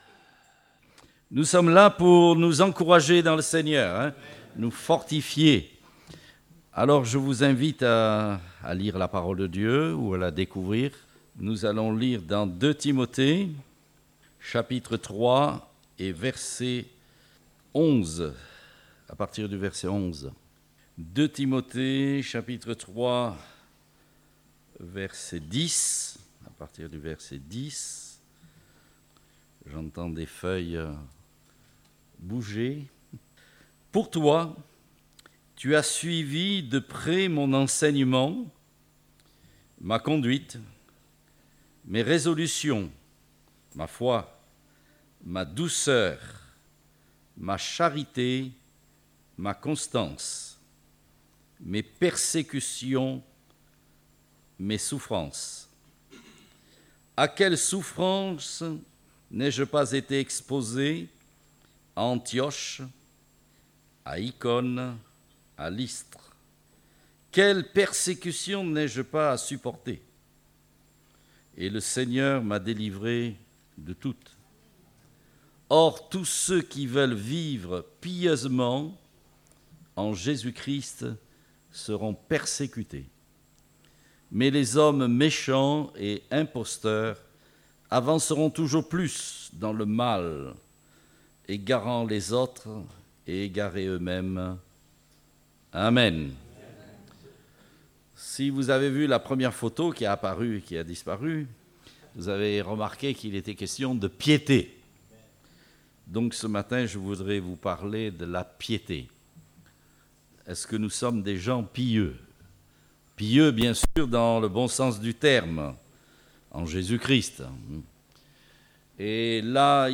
Date : 20 octobre 2019 (Culte Dominical)